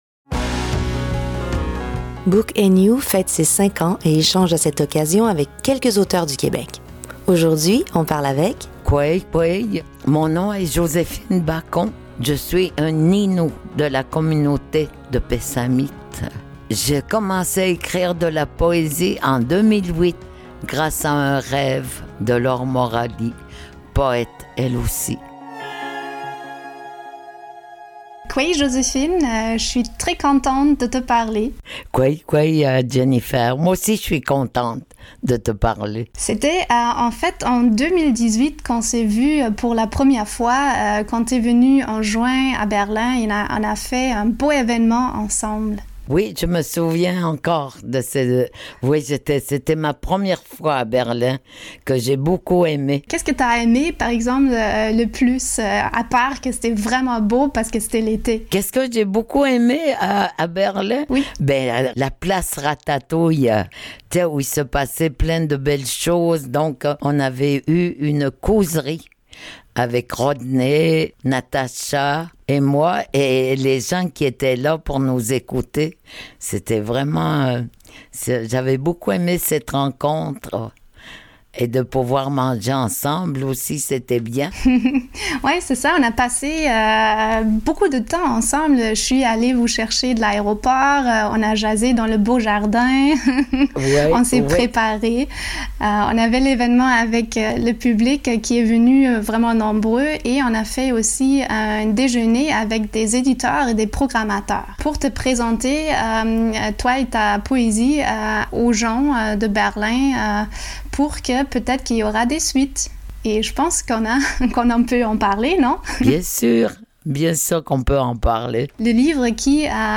invitée : Joséphine Bacon
voix : Catherine De Léan